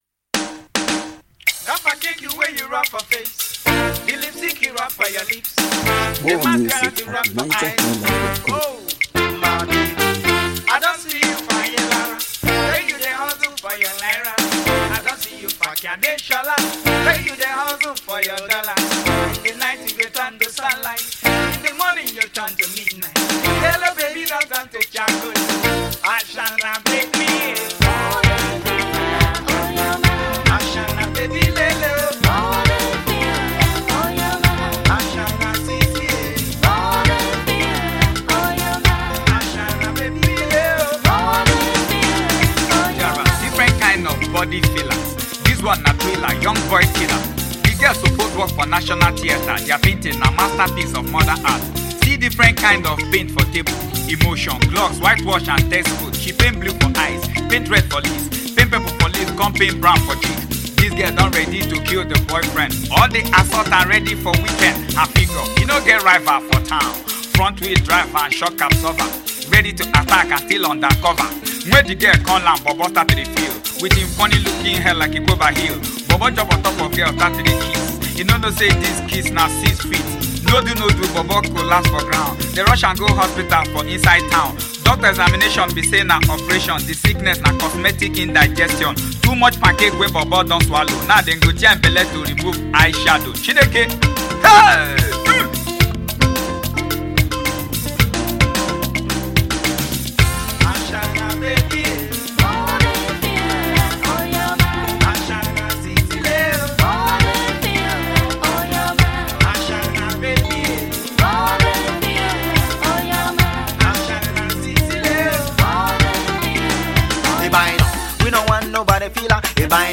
Home » Ragae